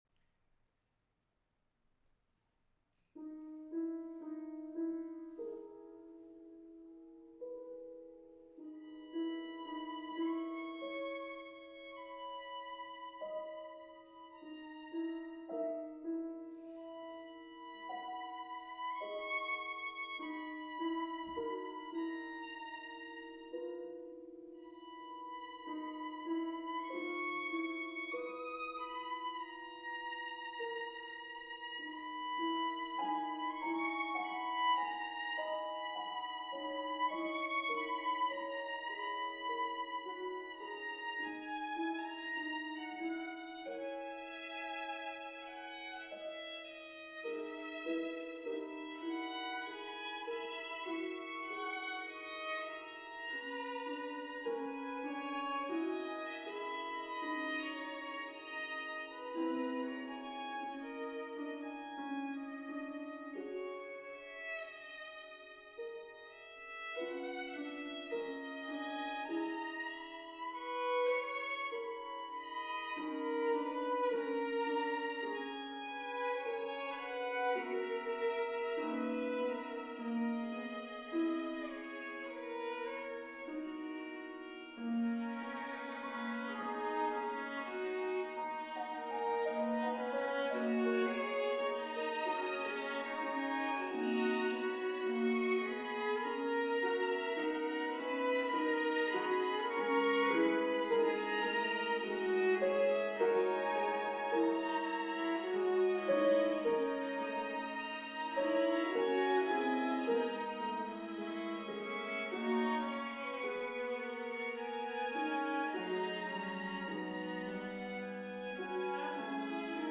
for Piano and String Quartet
Performance 20/20 Prism Concert
Berkman Recital Hall, The Hartt School, West Hartford, CT
Here, individual instruments are confined to their respective tiny area, playing their own tunes in solitude.
This is the world premiere recording.